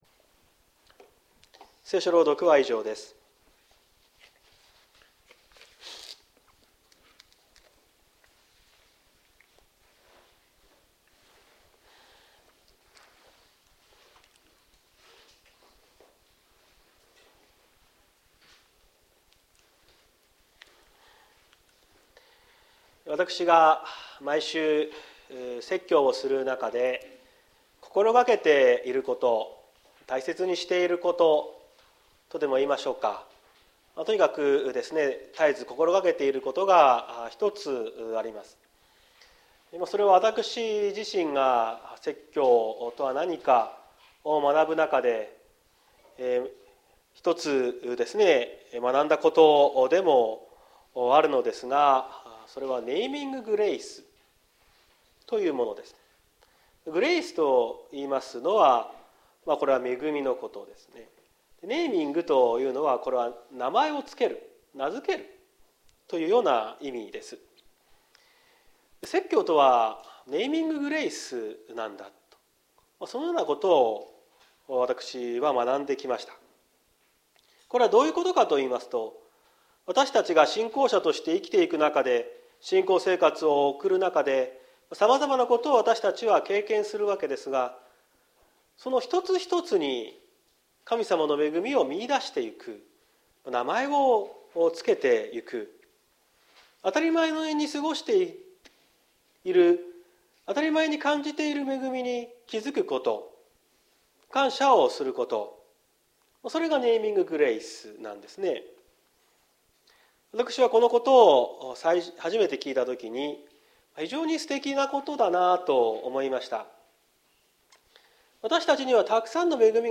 2023年03月05日朝の礼拝「最良のプレゼントを下さるおかた」綱島教会
説教アーカイブ。